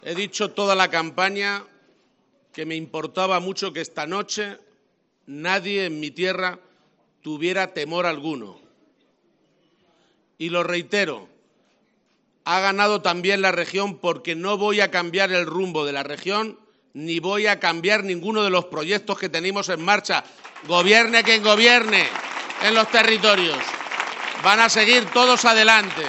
En el toledano Cigarral del Ángel Custodio, donde ha sido recibido al grito de “presidente”, Emiliano García-Page ha agradecido “de corazón” la contribución de “todos los que me habéis apoyado, me habéis ayudado y, por supuesto, a todos los que me habéis votado”, ha señalado.